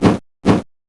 Heroes3_-_Vampire_-_MoveSound.ogg